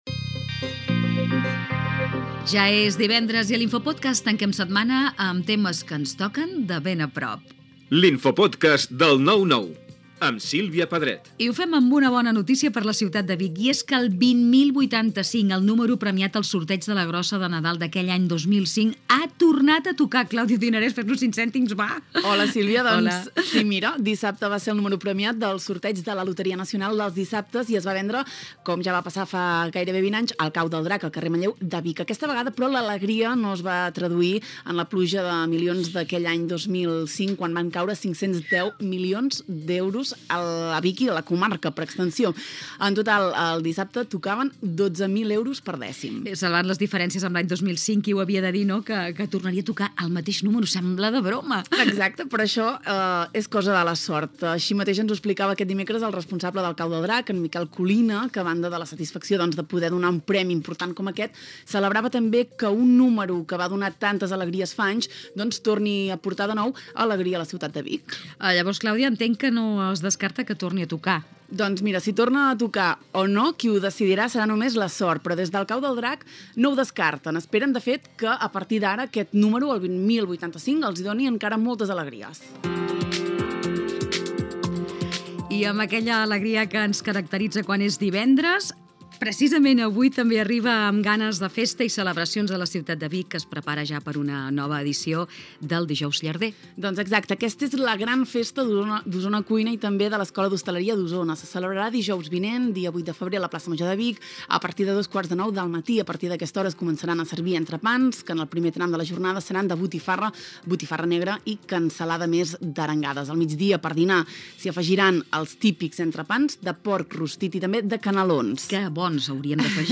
Careta del programa, un número premiat aquella setmana a la rifa ja havia sortit a l'any 2005 per Nadal, preparatius per al dijous gras, agenda cultural, careta de sortida
Informatiu